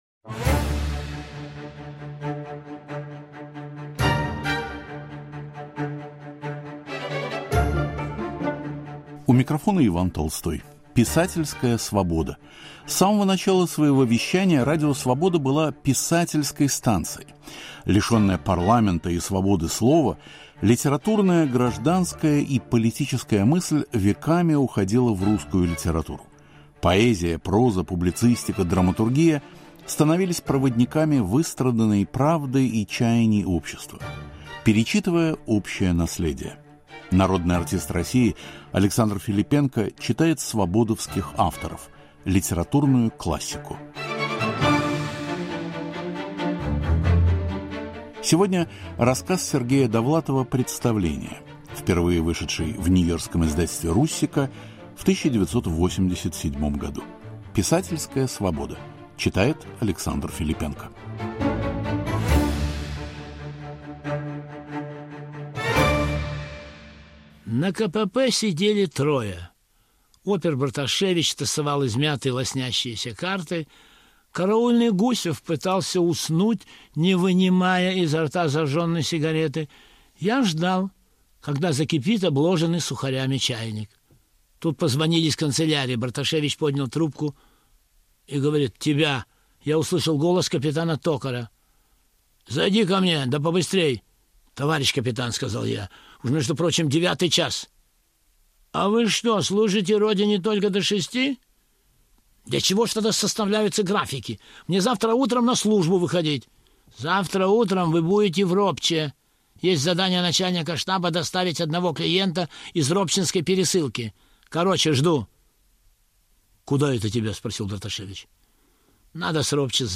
Александр Филиппенко читает рассказ Сергея Довлатова "Представление"